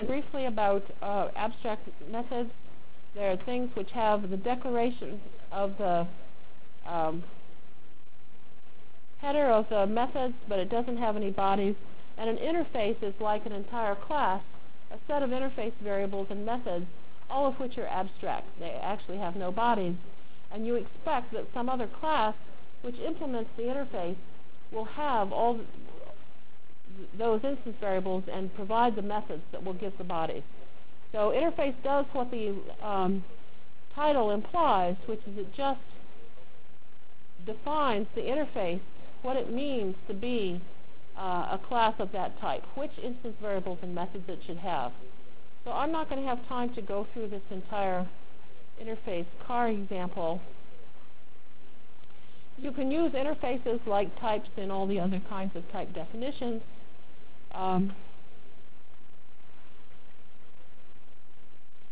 From Jan 27 Delivered Lecture for Course CPS616 -- Java Lecture 2 -- Basic Applets and Objects CPS616 spring 1997 -- Jan 27 1997.